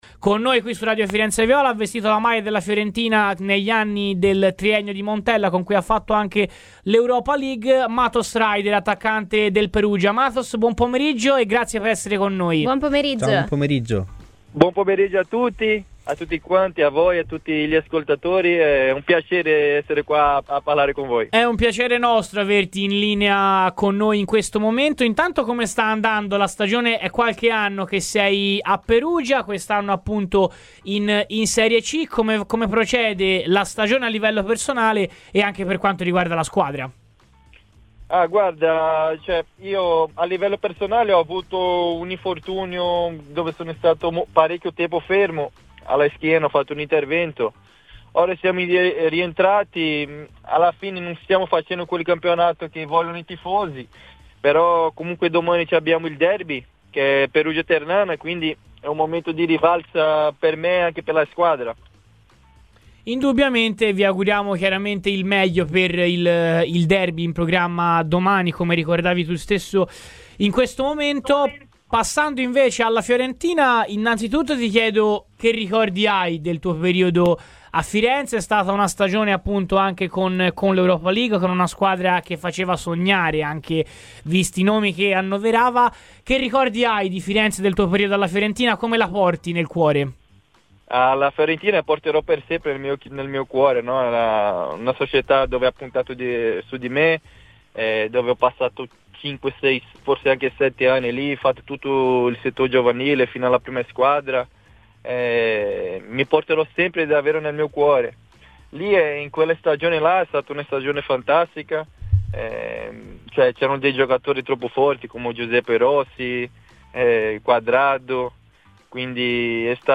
Ryder Matos, attualmente attaccante del Perugia ma con un passato nella prima Fiorentina di Montella e nel Verona, ha parlato ai microfoni di Radio FirenzeViola.